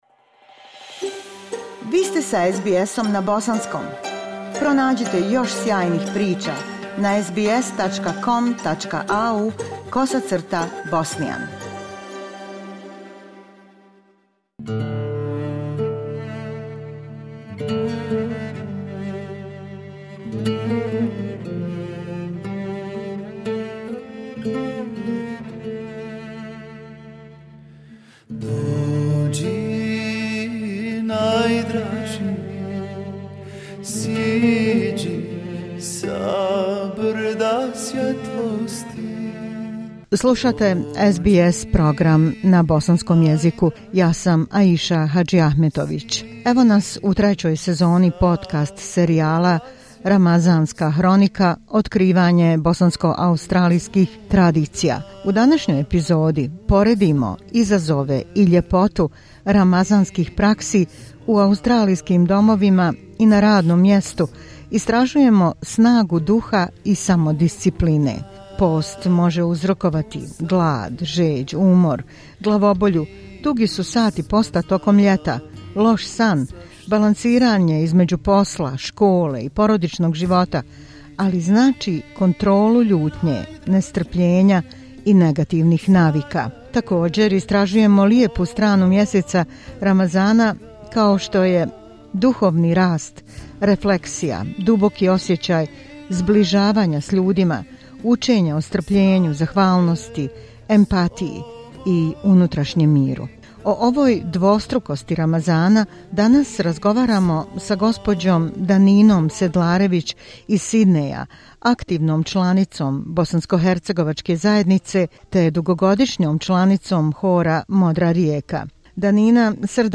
U razgovoru